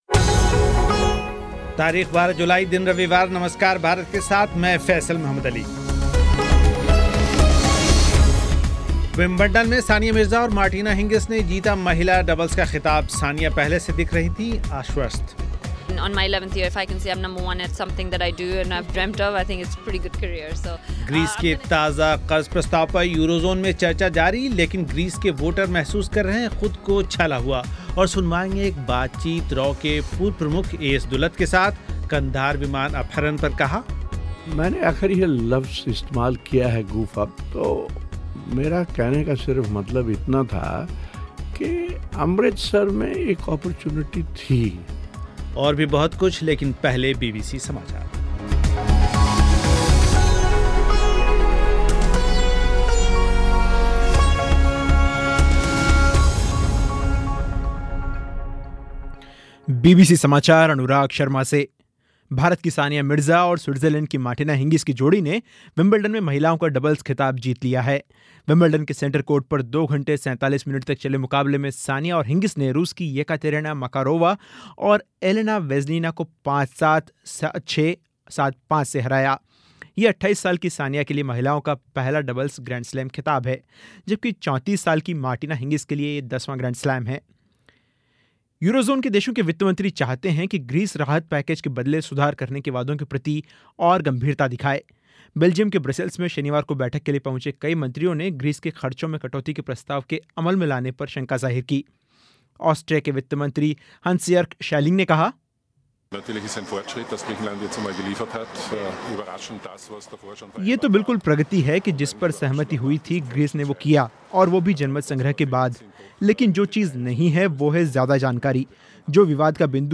एक बातचीत